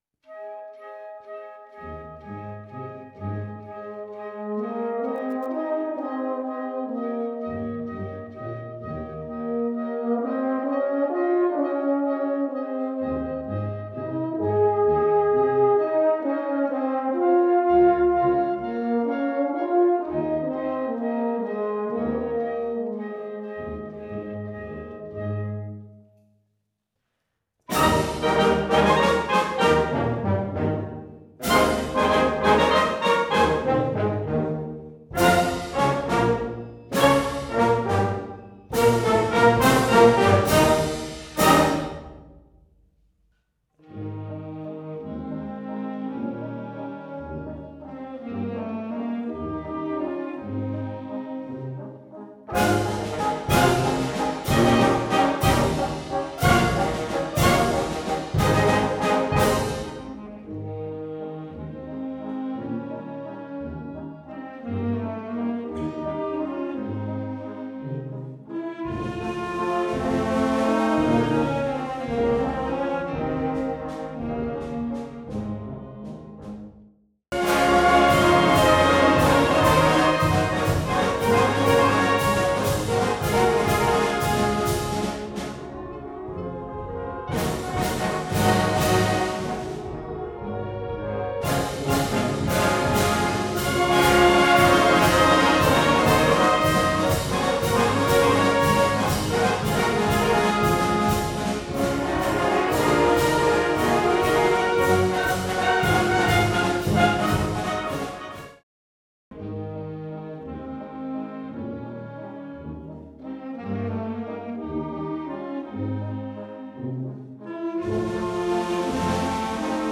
für Harmonie